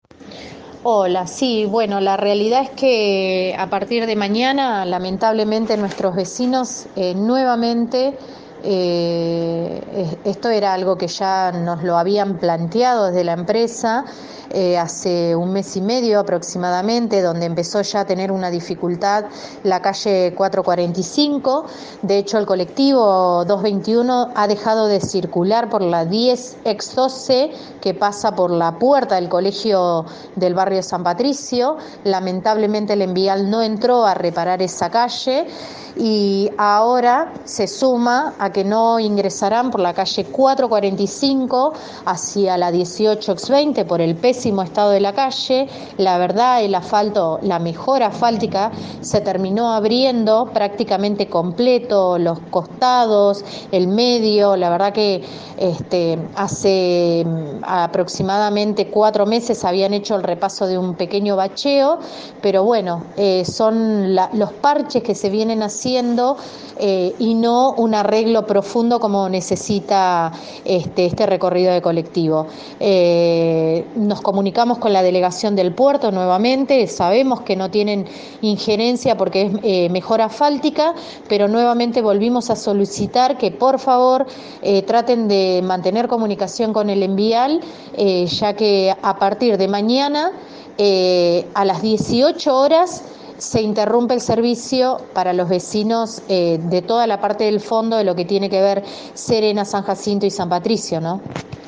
programa emitido de 7 a 9, por Radio de la Azotea